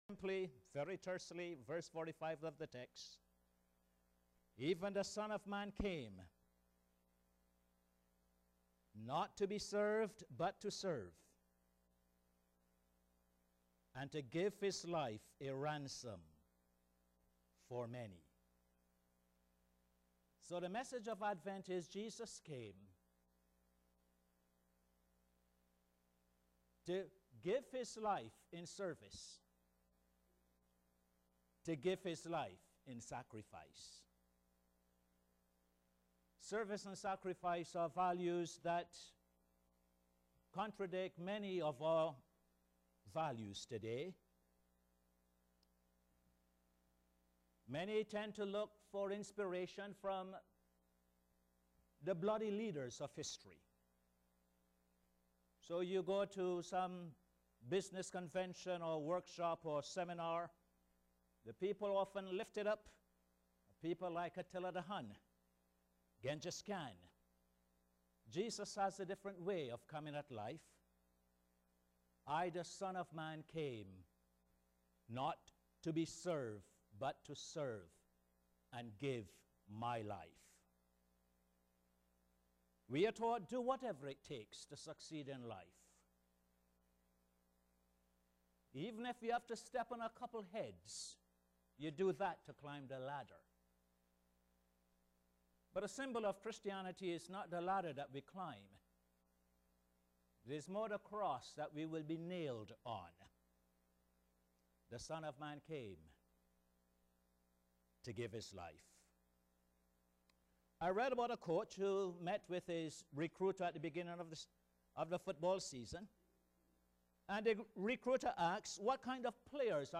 Posted in Sermons on 05. Dec, 2011